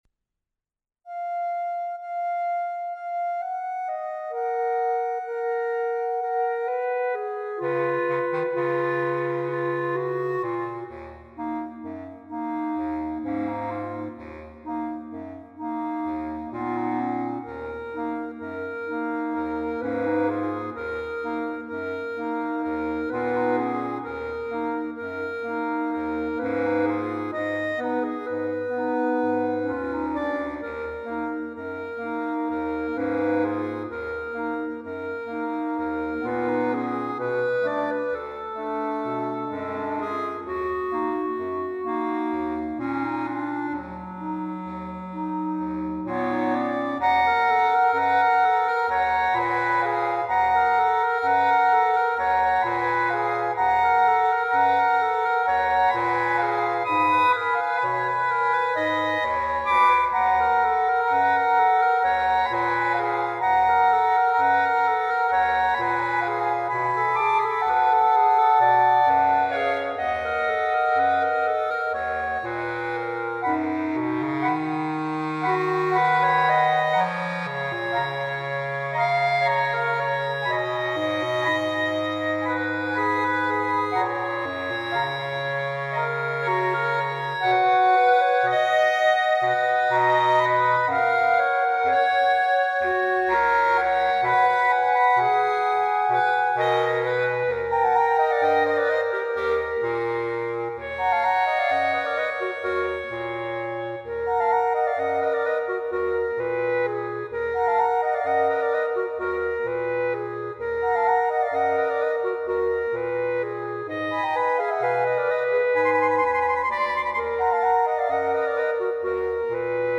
per quartetto di clarinetti
written in 7/4 utlizes a rich harmonic palette